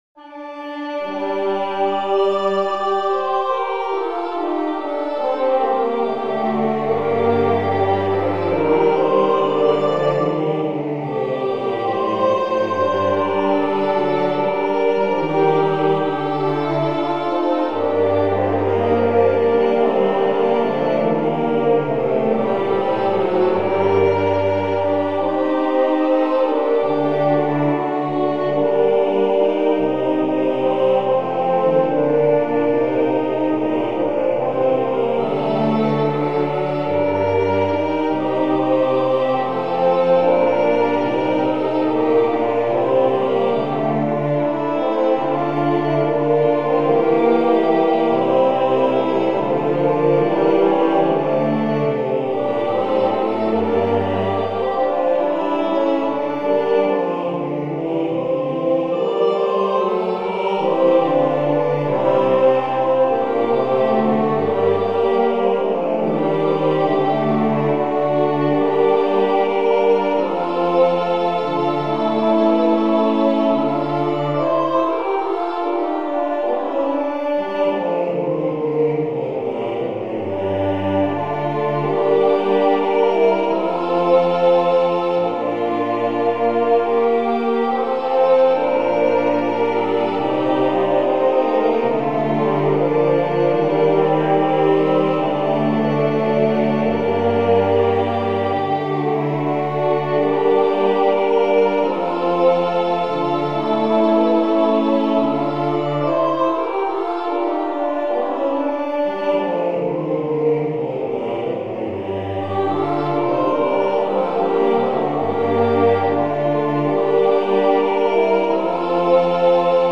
Motecta festorum totius anni cum communi sanctorum quaternis vocibus (Roma 1563)
midi ('alla semibreve' T = 72); 2'20"